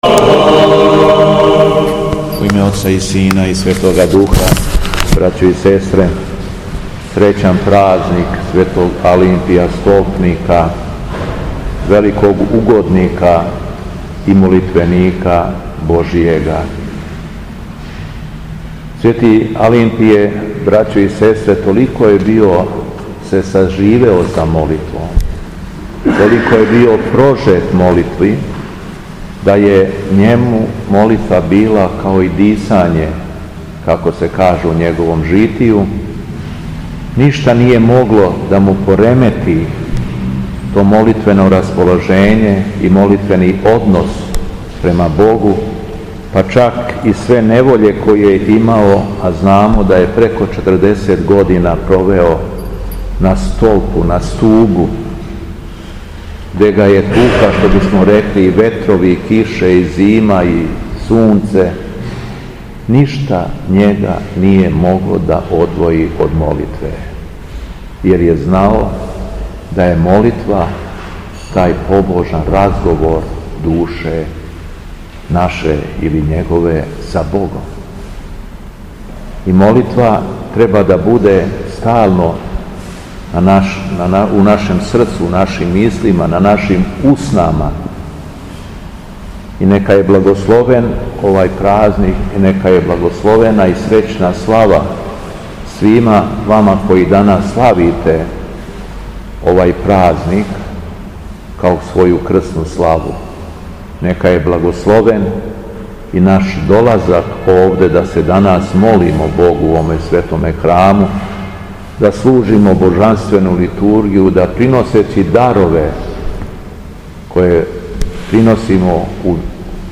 Беседа Његовог Високопреосвештенства Митрополита шумадијског г. Јована
У наставку свете литургије, по прочитаном Јеванђељу, Високопреосвећени обратио се верном народу: